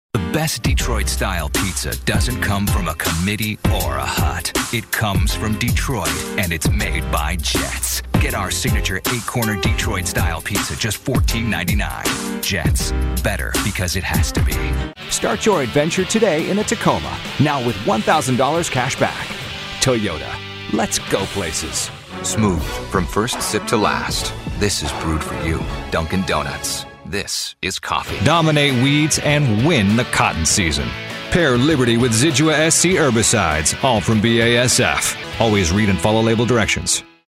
Contemporary, Real, Persuasive.
Commercial